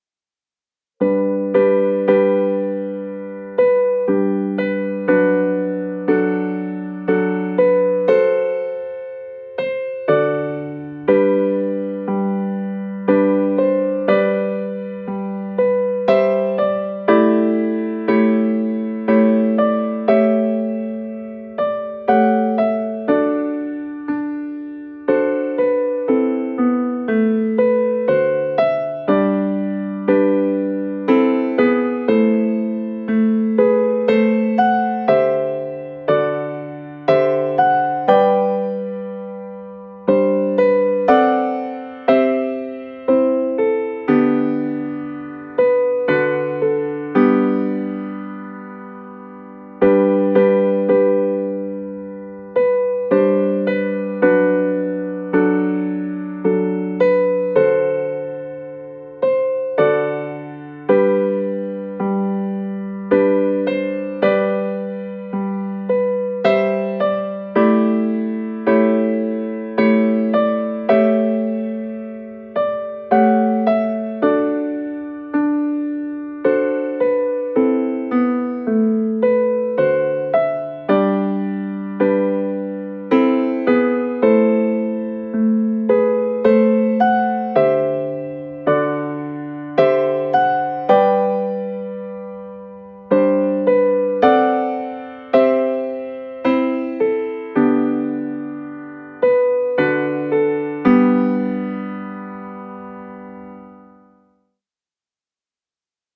interprétés dans une atmosphère chaleureuse et raffinée.